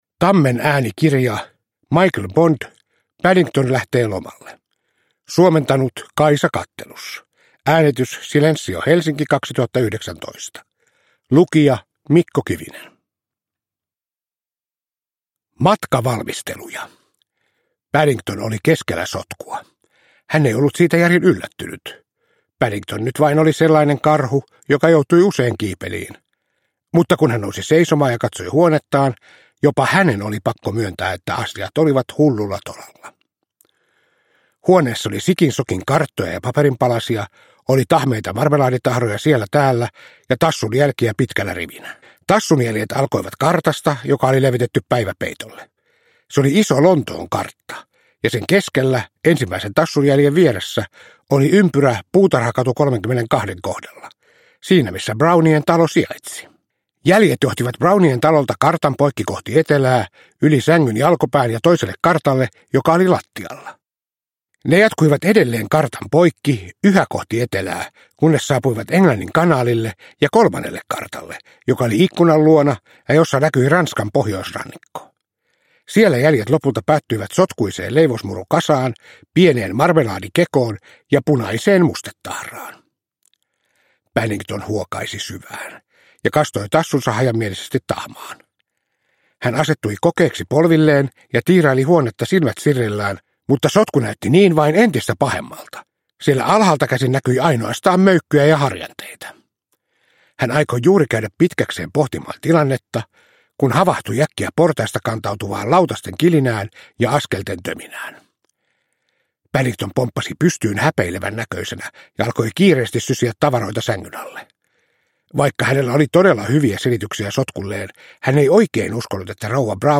Paddington lähtee lomalle – Ljudbok – Laddas ner